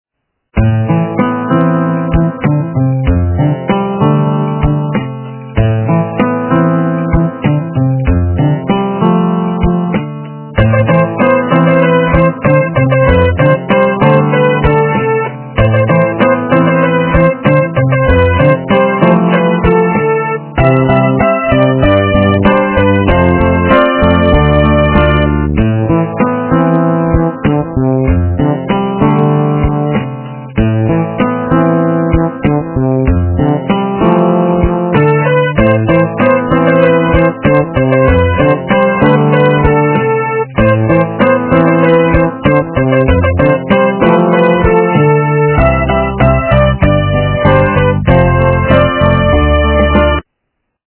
рок, металл